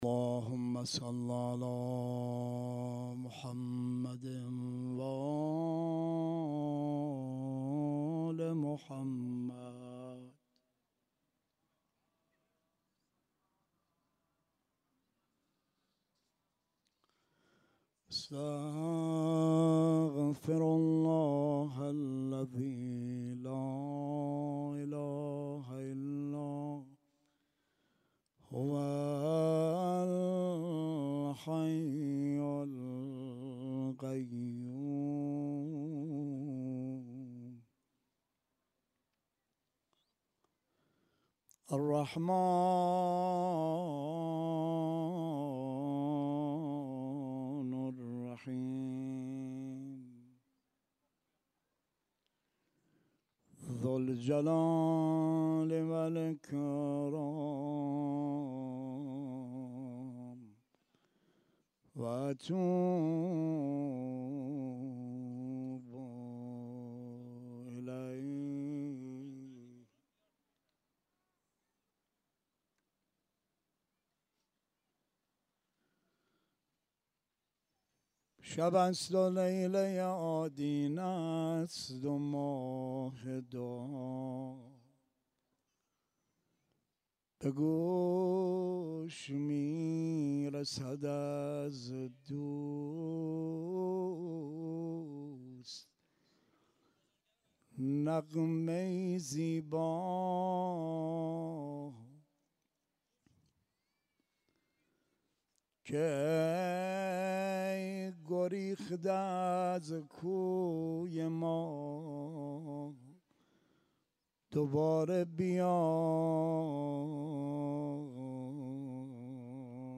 مناجات خوانی